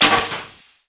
stakeGun.WAV